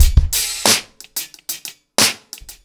• 90 Bpm Breakbeat Sample D Key.wav
Free drum beat - kick tuned to the D note. Loudest frequency: 3935Hz
90-bpm-breakbeat-sample-d-key-nhy.wav